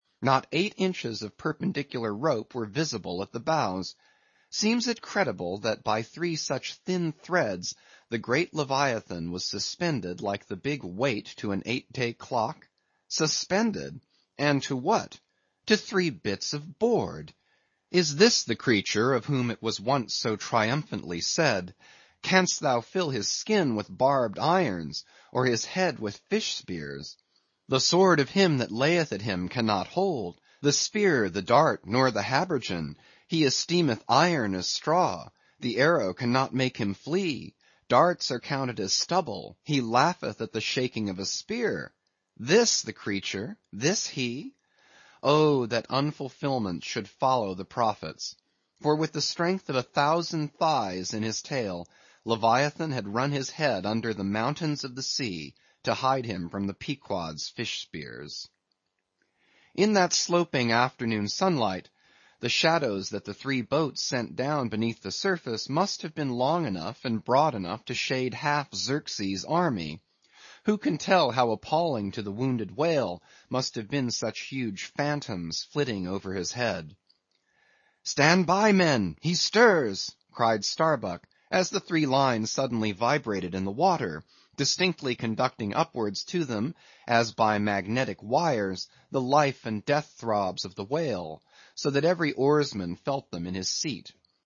英语听书《白鲸记》第712期 听力文件下载—在线英语听力室